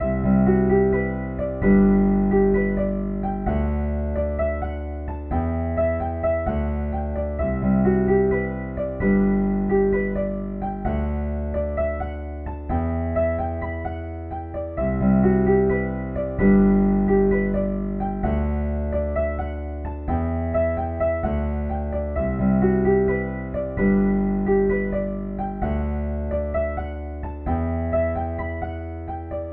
钢琴
描述：小型三角钢琴
Tag: 130 bpm Trap Loops Piano Loops 4.97 MB wav Key : Unknown FL Studio